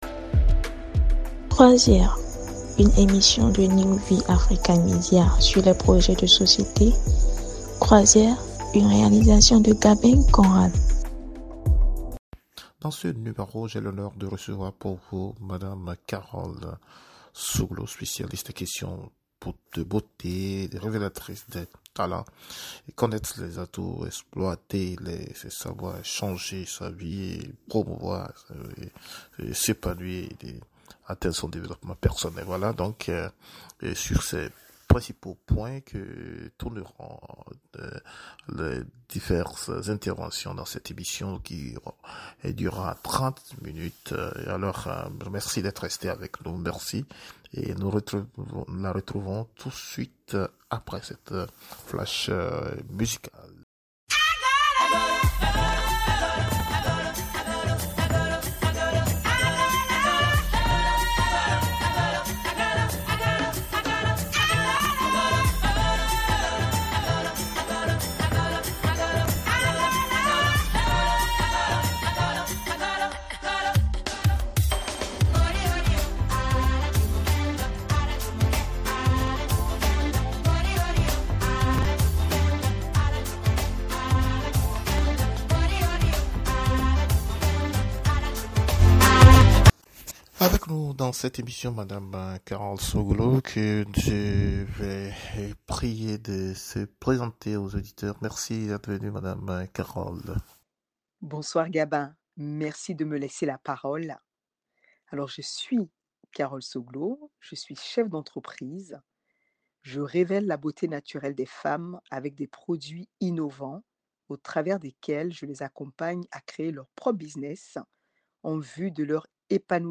Découvrez dans cet entretien les secrets sur l'entretien de la peau, les meilleures solutions pour le développement personnel et les meilleures stratégies pour booster son entreprise.